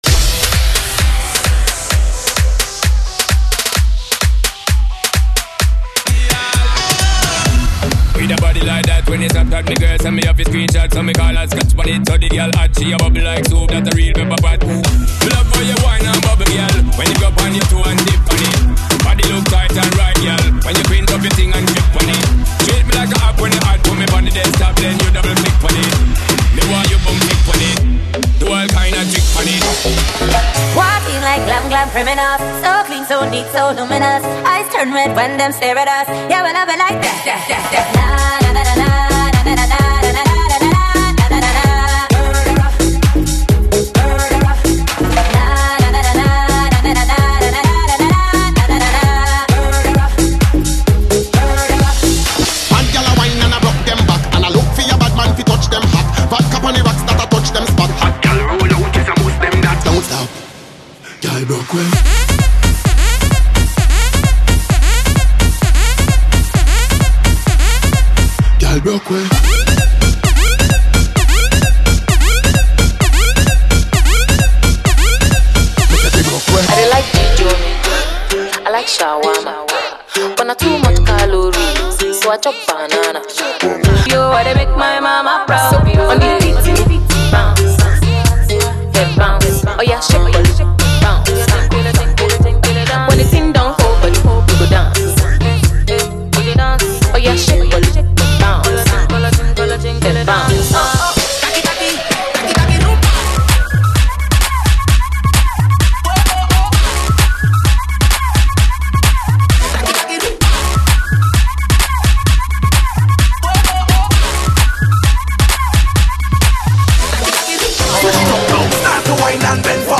FORMAT: 32COUNT